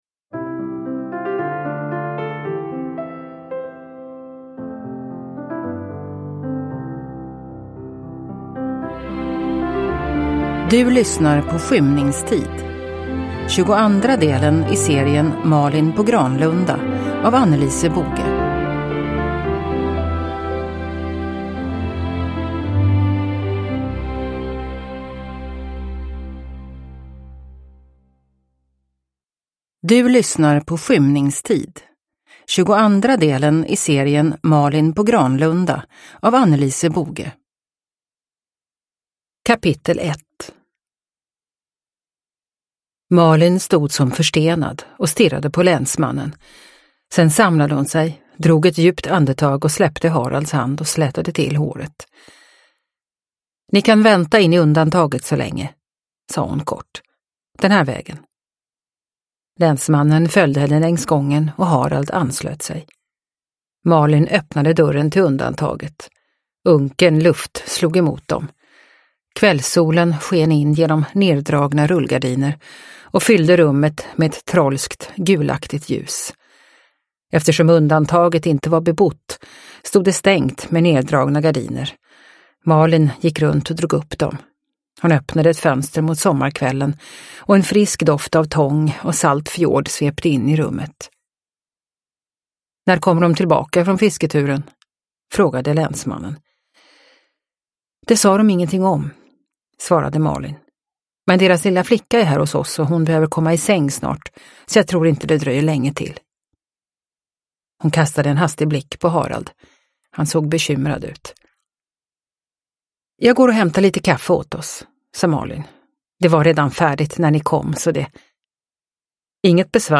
Skymningstid – Ljudbok – Laddas ner